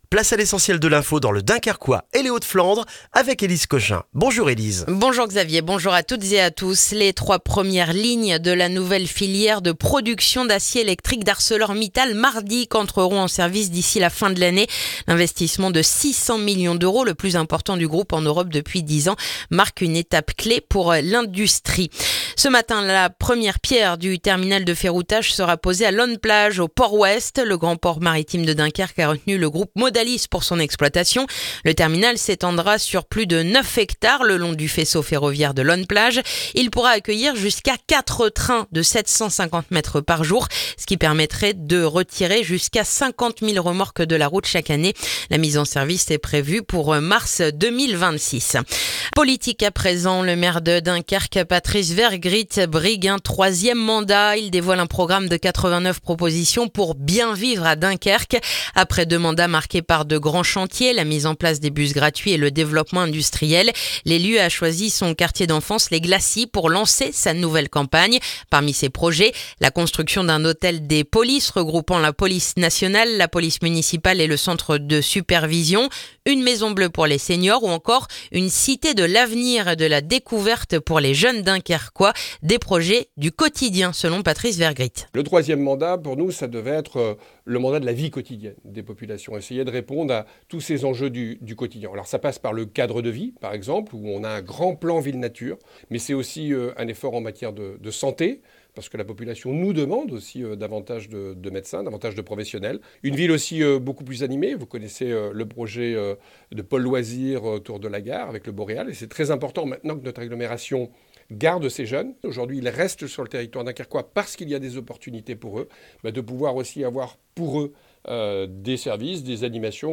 Le journal du mardi 25 novembre dans le dunkerquois